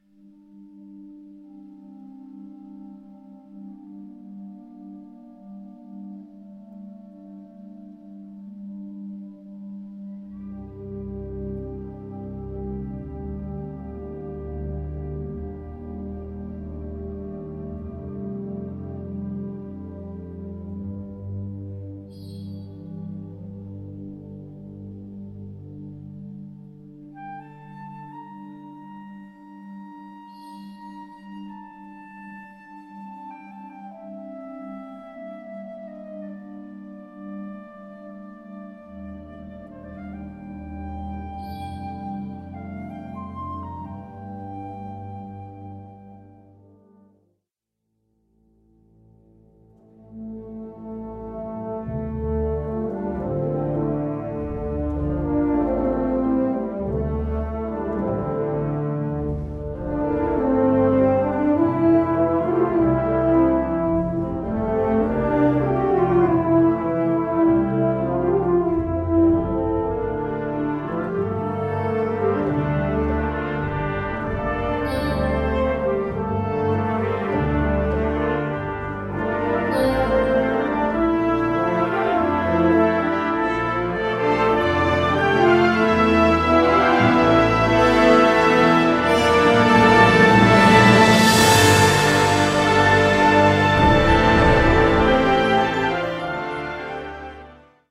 Kategorie Blasorchester/HaFaBra
Adagio teneramente